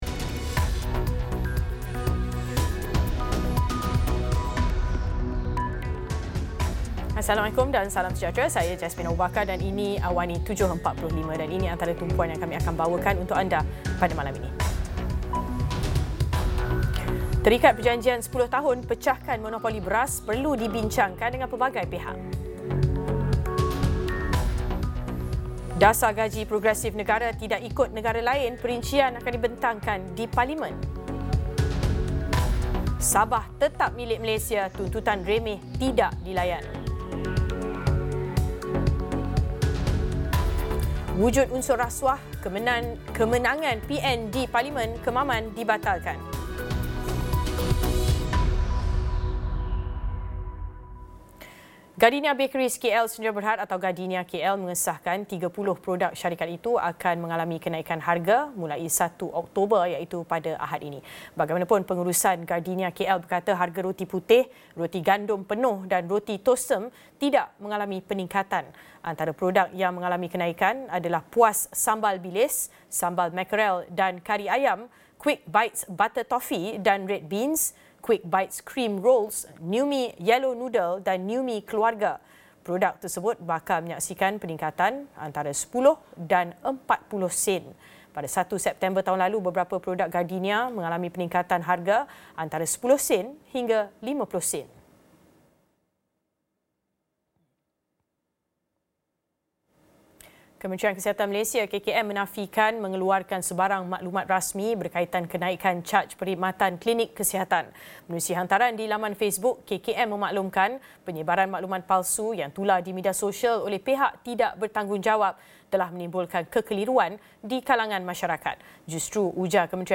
Laporan berita padat dan ringkas